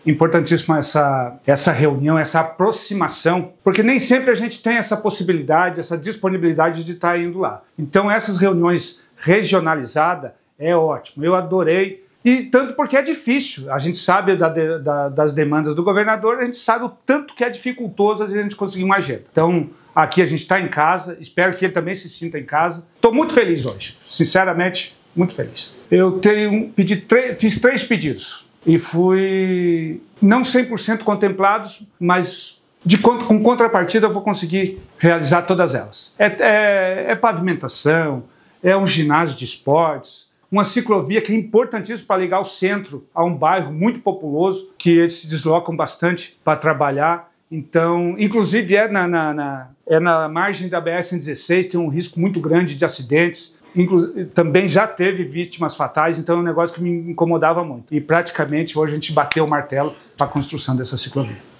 Após a conversa individual com o governador Jorginho Mello, o prefeito de Ponte Alta, Edson Julio Wolinger, destacou algumas obras que serão realizadas no município com o aporte do Governo do estado: